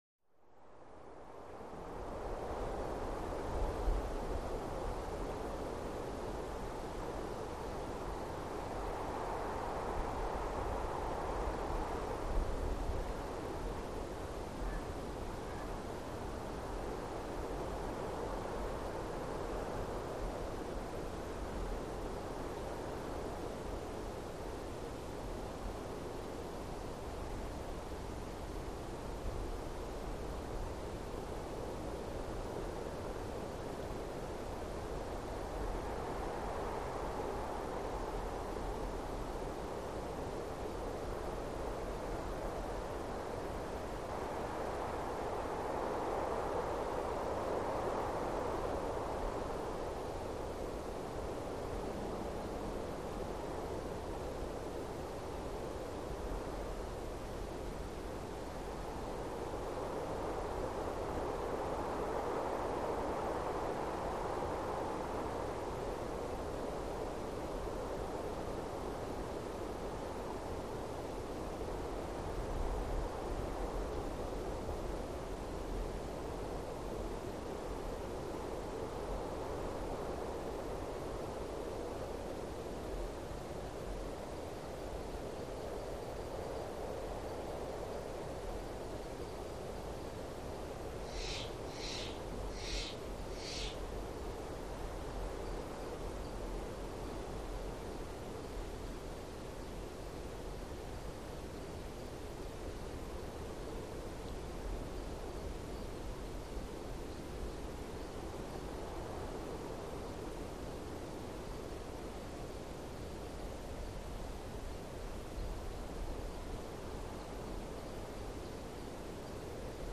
LightWindTreesCold PE030401
Light Wind 4; Steady In Trees, Cold And Desolate With Sparse Distant Bird Chirps And Crow Caws.